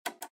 minislot_click_spin.mp3